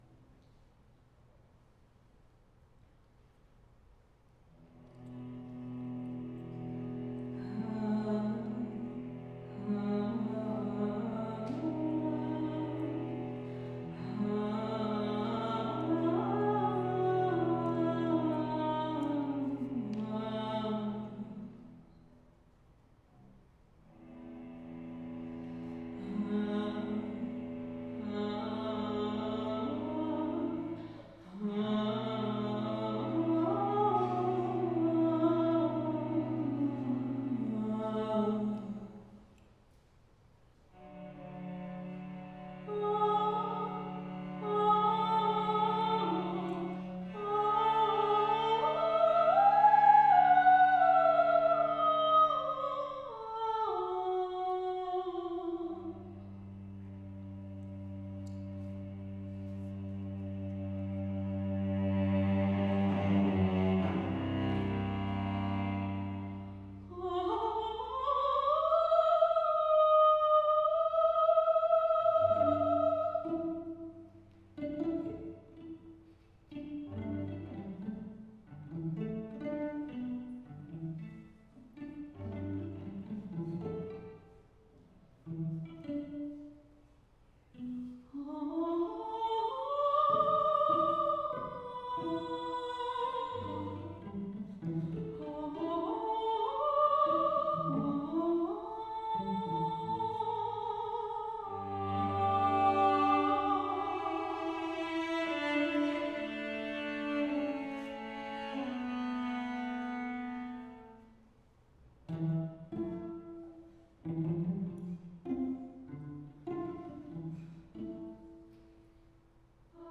Soprano, Faculty Artist Recital